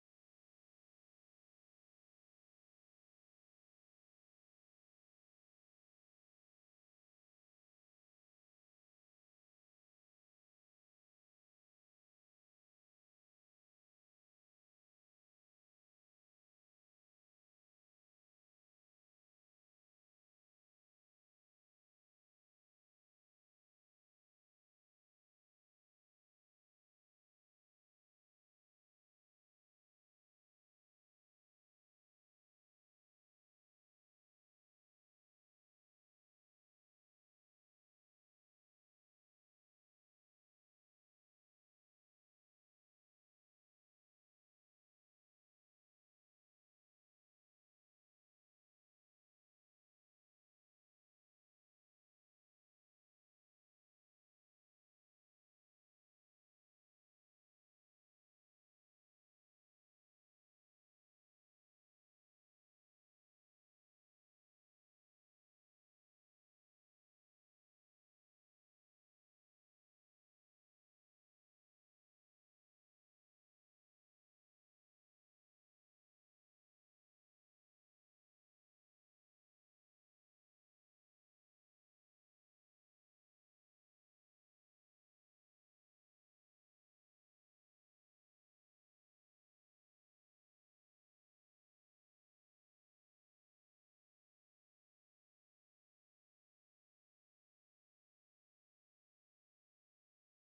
ライブ・アット・カーネギーホール、ニューヨーク、 07/03/1974
※試聴用に実際より音質を落としています。